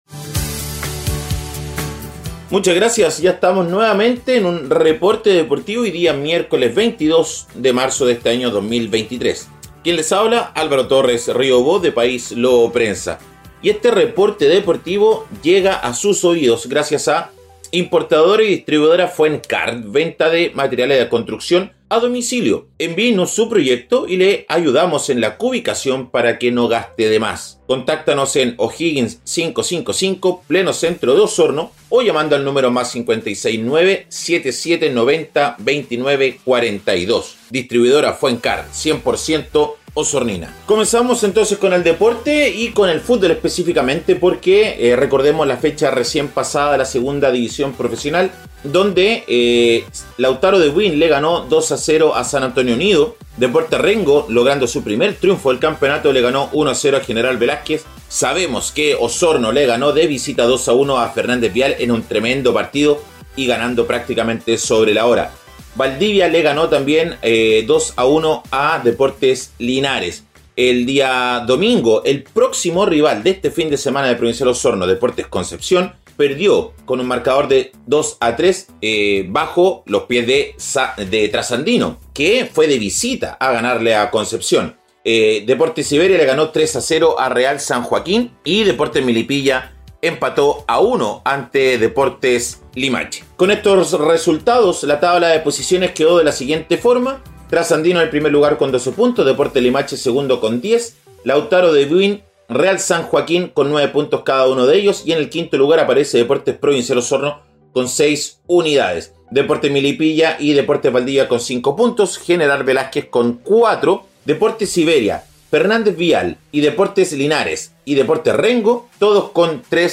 Reporte Deportivo 🎙 Podcast 22 de marzo de 2023